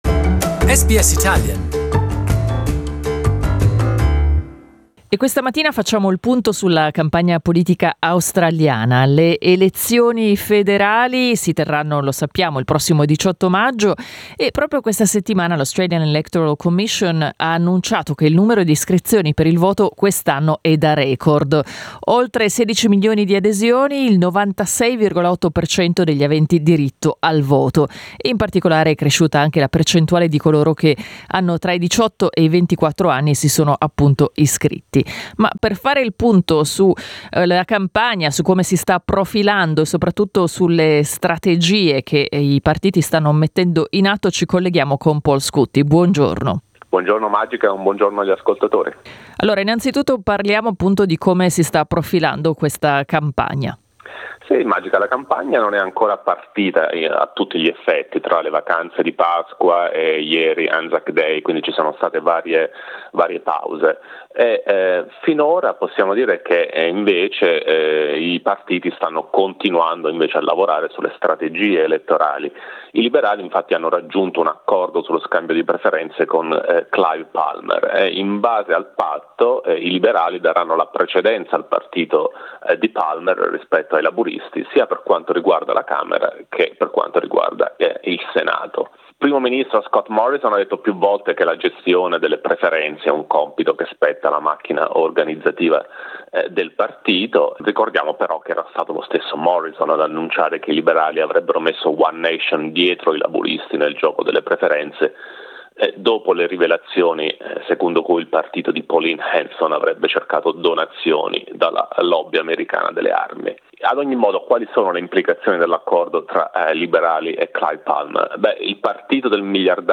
Political commentator